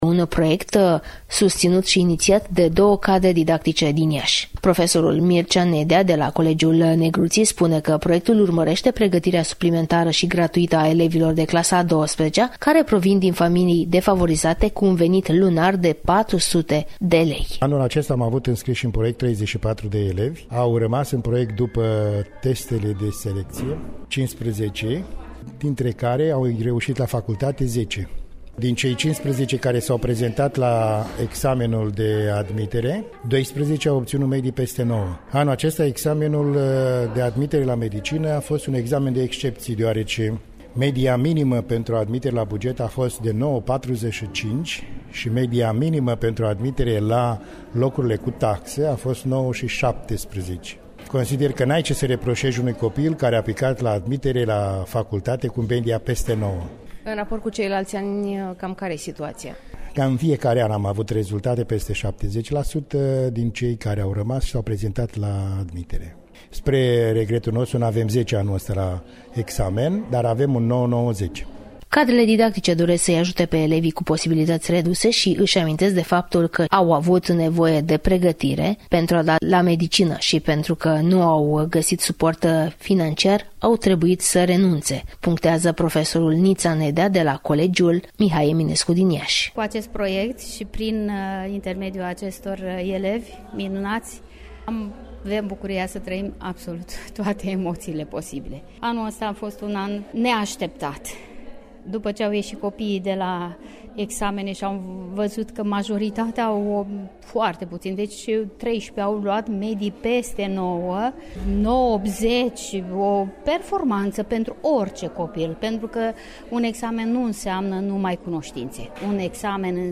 (REPORTAJ) Doi profesori din Iaşi fac meditaţii gratuite pentru Medicină